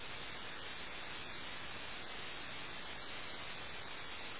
blank.mp3